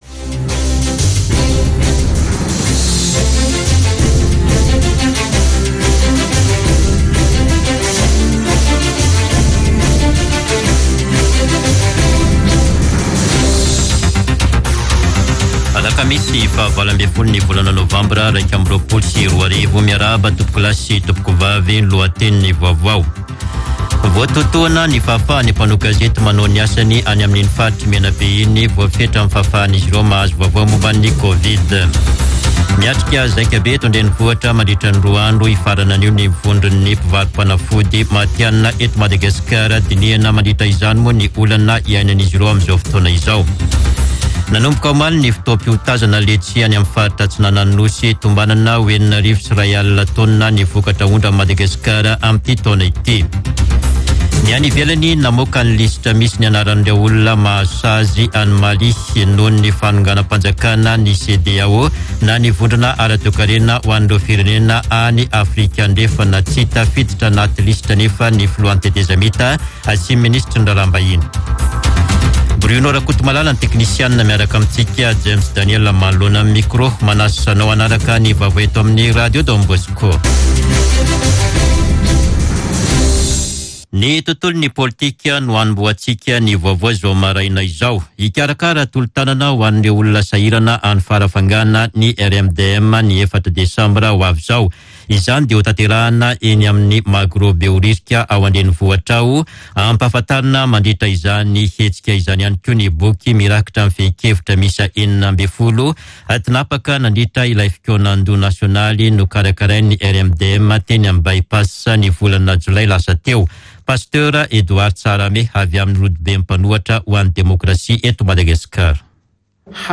[Vaovao maraina] Alakamisy 18 novambra 2021